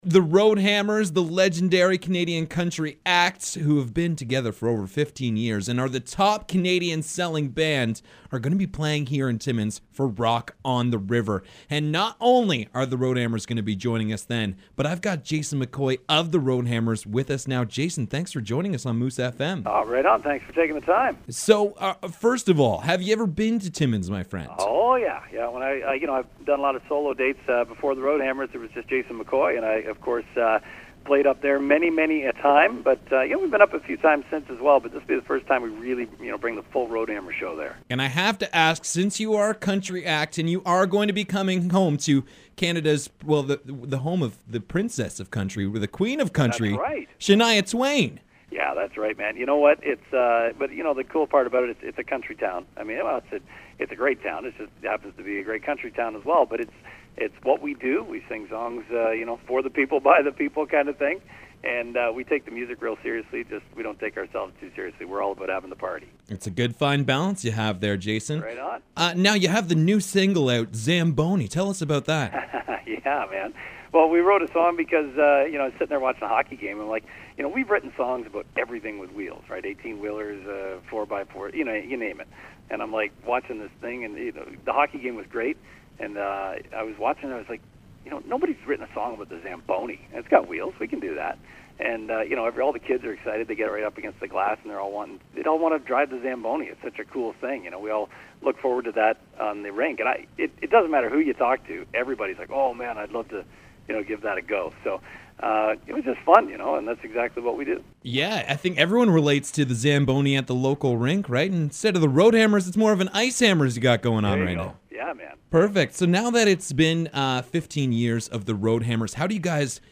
Have a listen to the full conversation with Jason McCoy of The Road Hammers below…